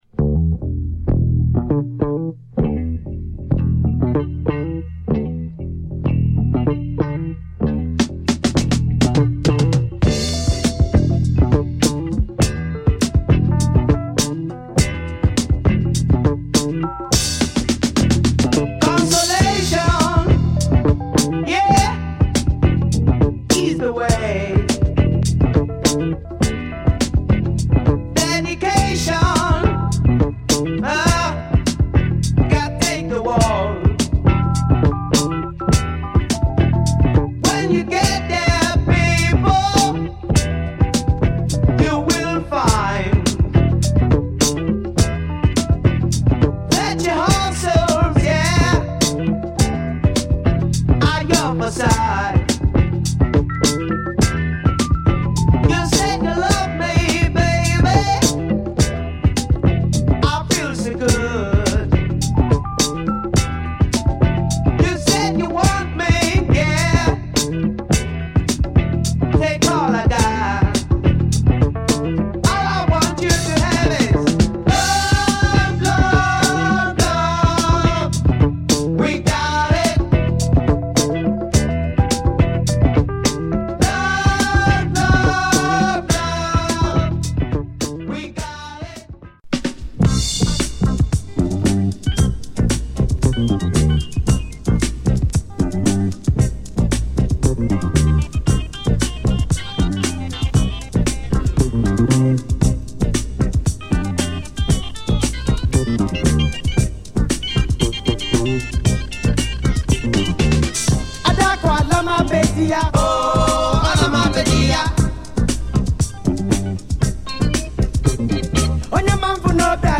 よりモダン・ソウル、ディスコ、ブギーに接近した最高のアフロ・ディスコ作